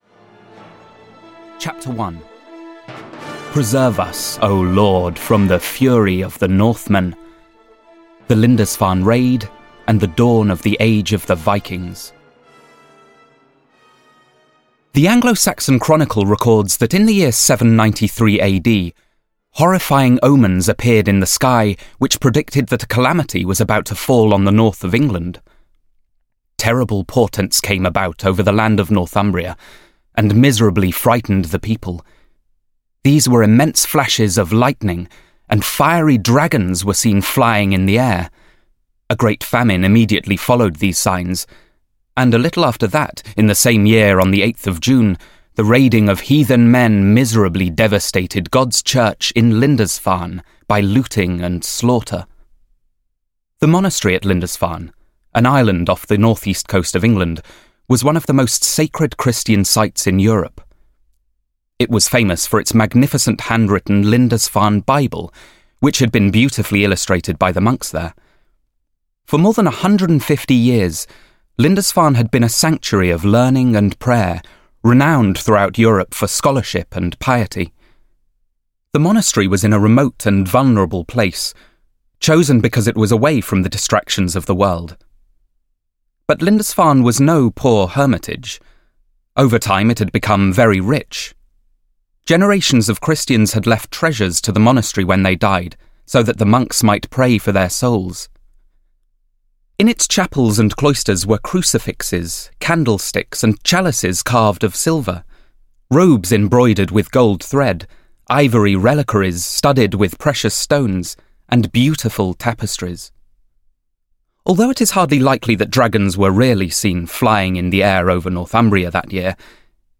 The Vikings (EN) audiokniha
Ukázka z knihy